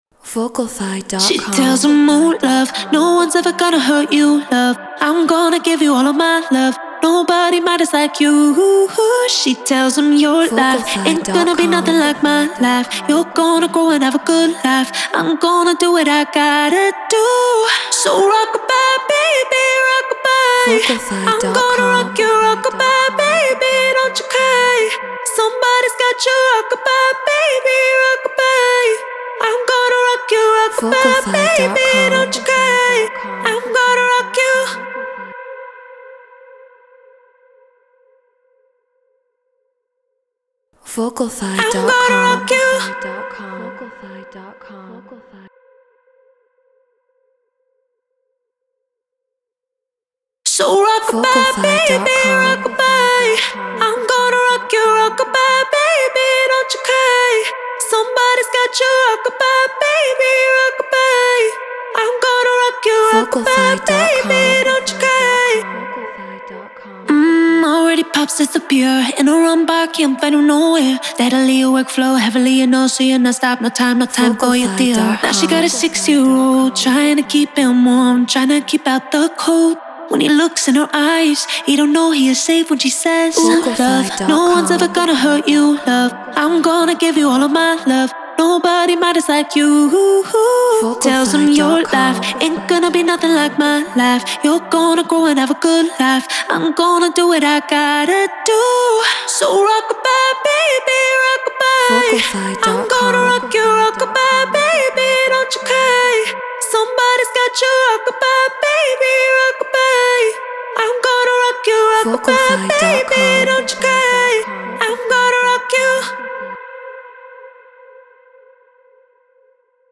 Afro House 118 BPM Cmaj
Cover Vocal